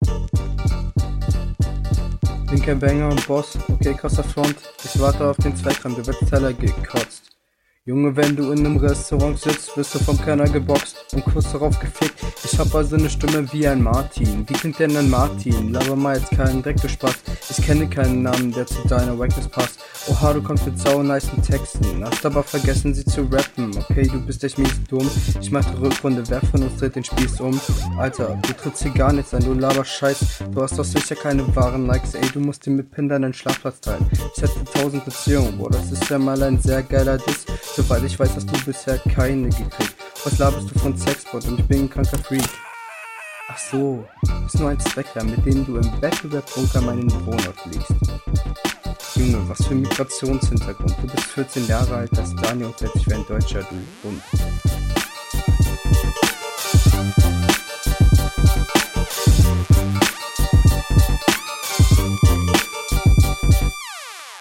Flow: ist deinem gegner unterlegen Text: ebenso leicht unterlegen Soundqualität: muss sich auch verbessern Allgemeines: …
Flow: eigentlich kein flow vorhanden.
Flow: Ansatzweise ist dein Flow flüssig, aber dann bricht er manchmal leider abrupt ab, wenn …